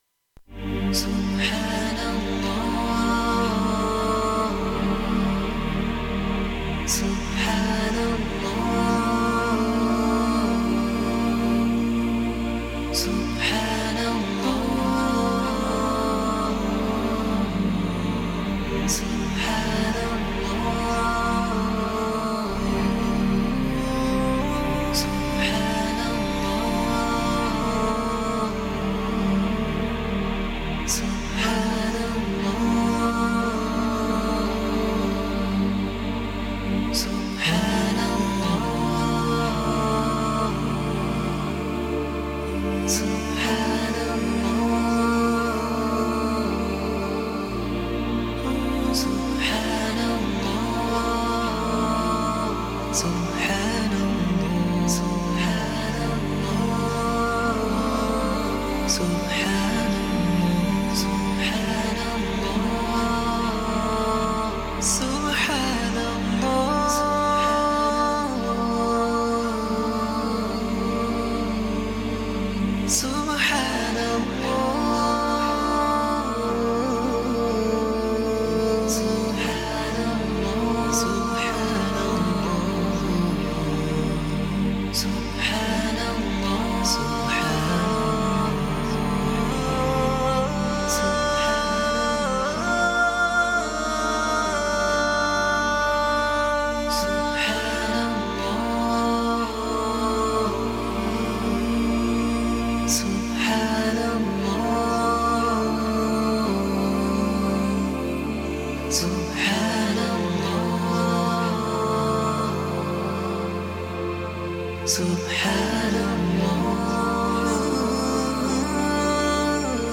Música [Nashid]